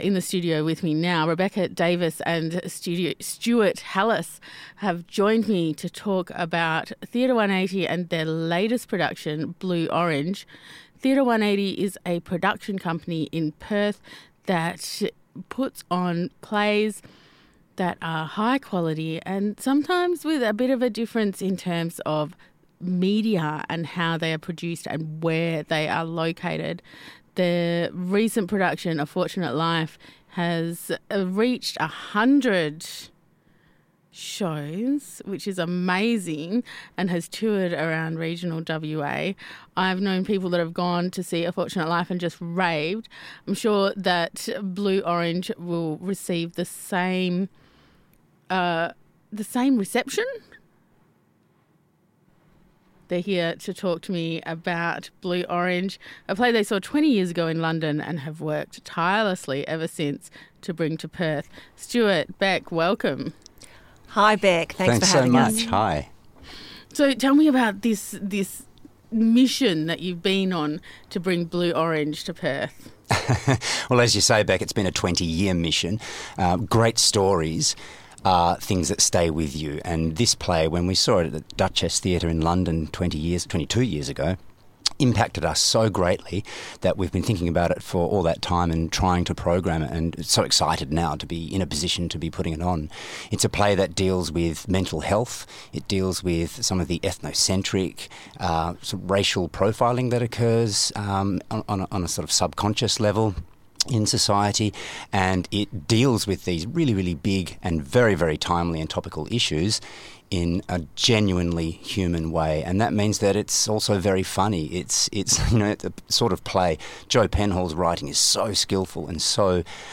in the RTRFM studio for Artbeat.